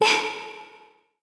Isaiah-Vox_Happy1_r_jp.wav